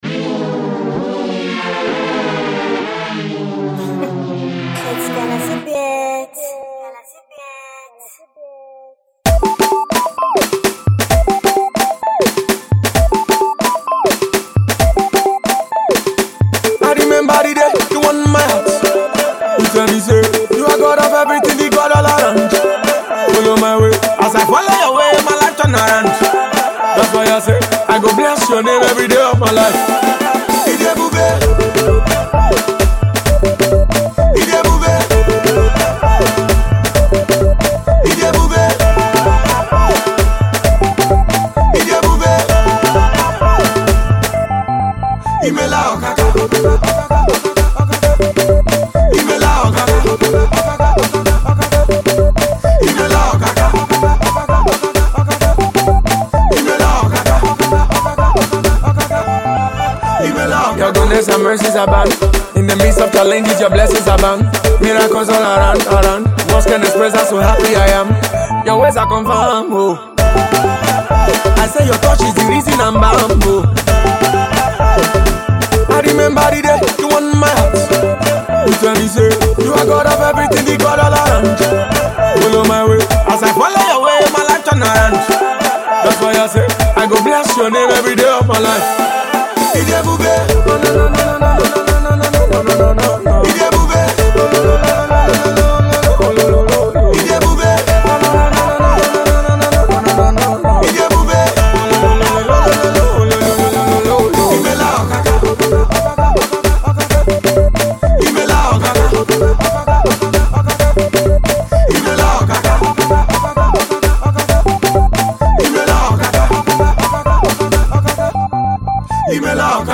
praise banger